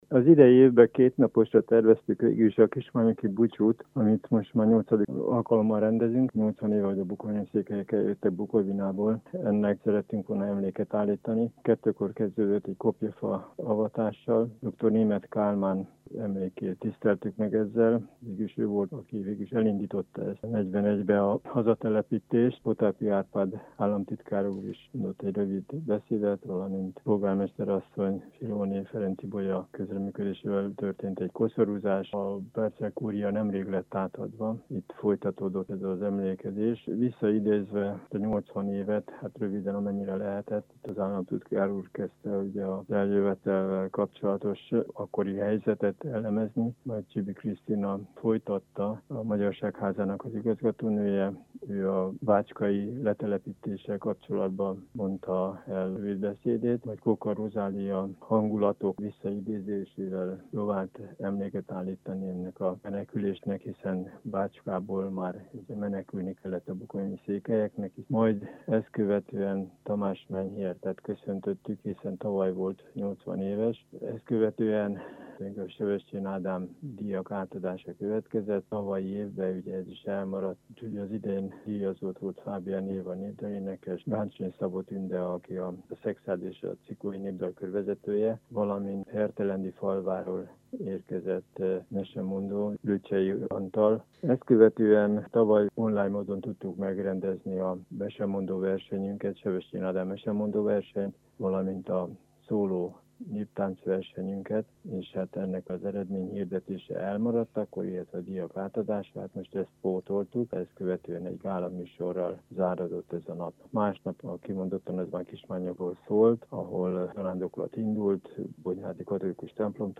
A gyermekek között nem jellemző a koronavírus terjedése, náluk inkább az ősszel gyakori nátha és különböző felsőlégúti megbetegedések a gyakoriak – nyilatkozta Híradónknak az egyik győri gyermekháziorvos.